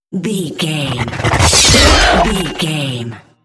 Whoosh metal sword creature
Sound Effects
dark
intense
whoosh